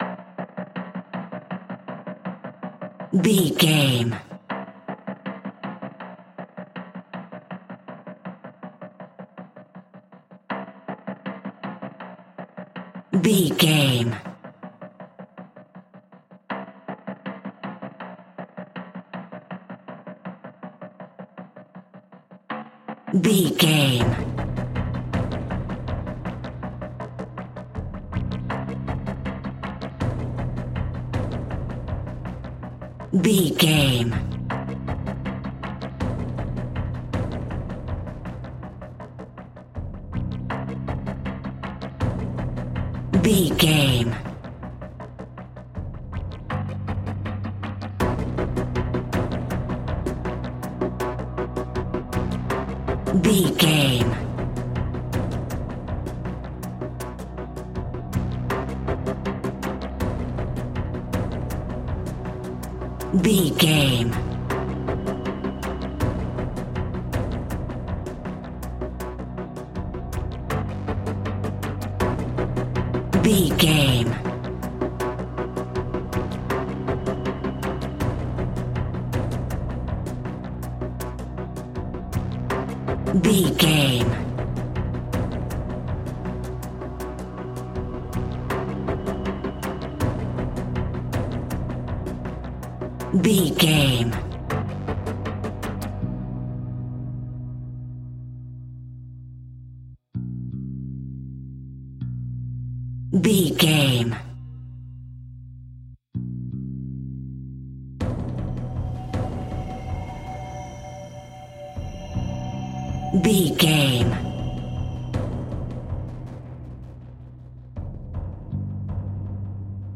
In-crescendo
Aeolian/Minor
scary
ominous
dark
haunting
eerie
epic
drums
synthesiser
piano
strings
horror music